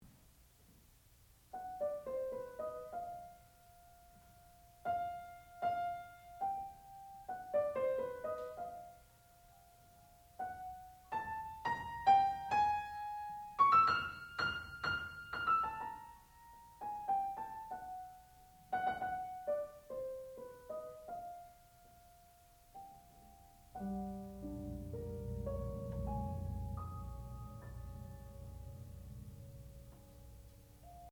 Seven Scenes for Piano and Percussion
sound recording-musical
classical music